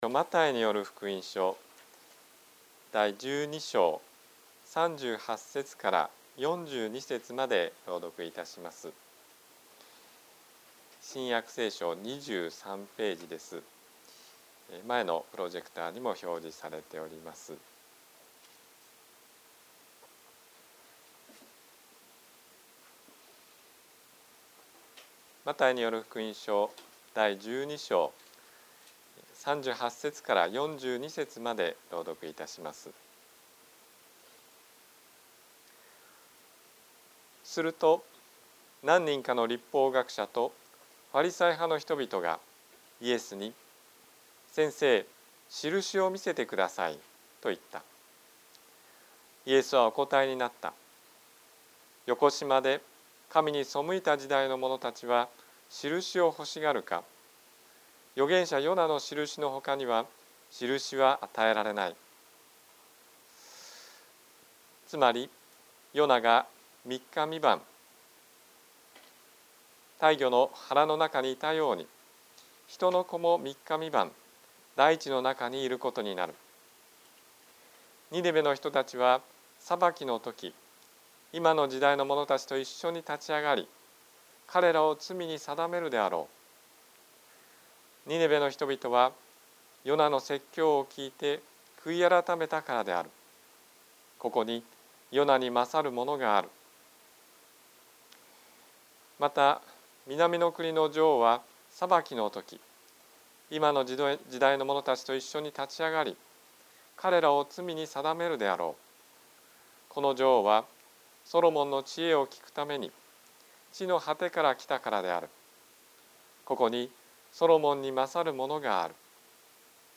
説教アーカイブ。
日曜 朝の礼拝